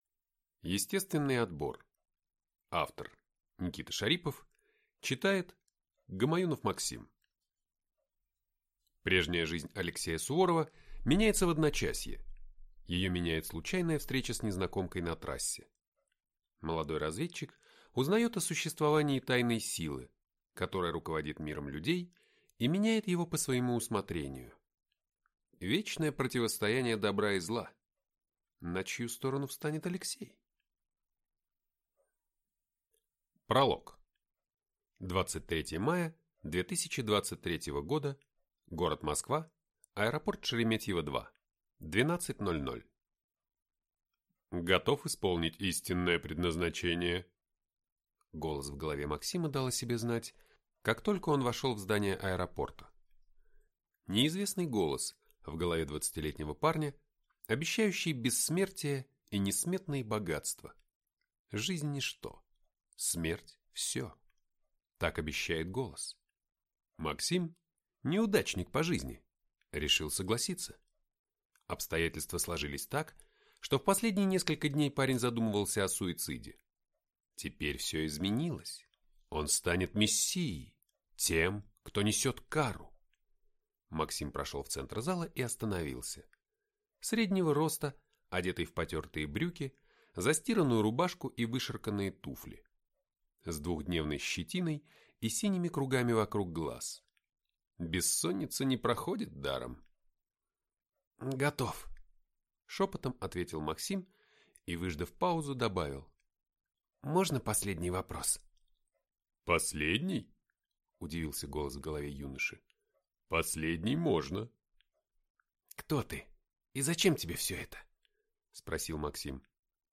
Аудиокнига Выжить любой ценой. Часть четвертая. Естественный отбор | Библиотека аудиокниг